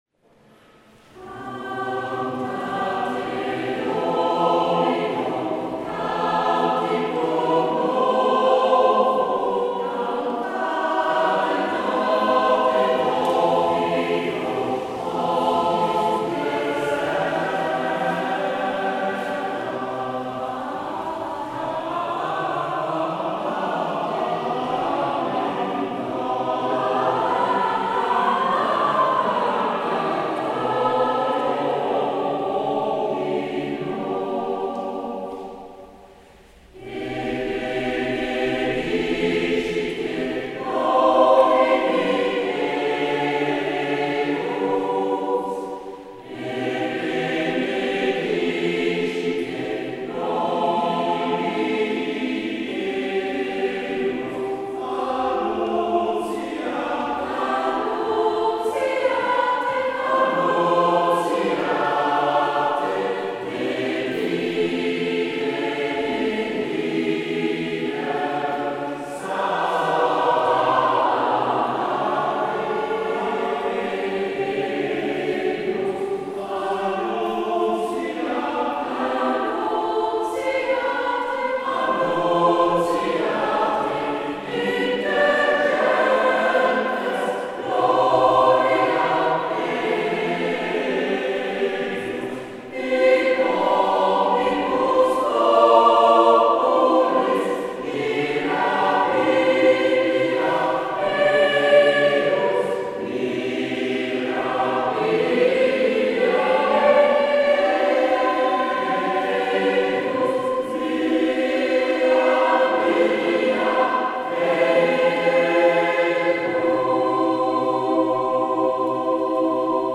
Cantate Domino (live in de St.-Carolus Borromeuskerk te Antwerpen)
Cantate-Domino-live-in-de-St.-Carolus-Borromeuskerk-te-Antwerpen.mp3